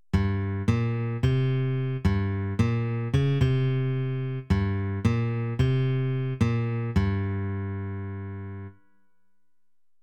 # create effect melody (original + octave lower + fifth lower)
When saved, the two MIDI files sound like this (first the original, then the effect):